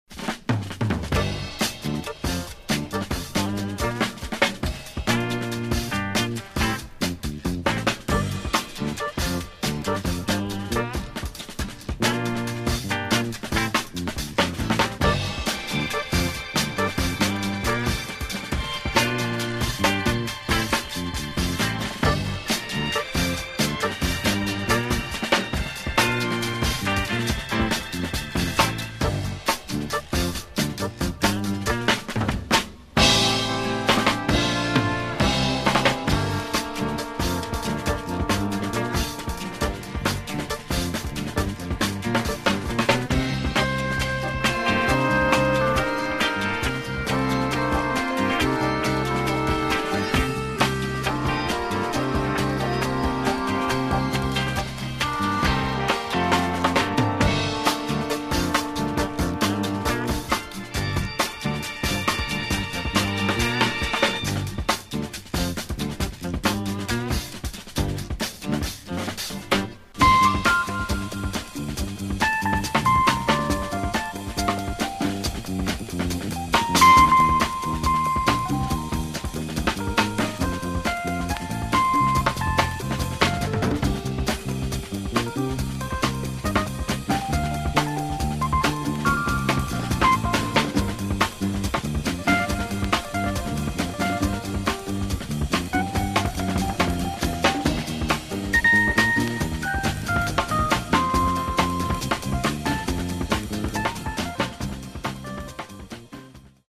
Rare French issue for this classic groovy soundtrack.
electric piano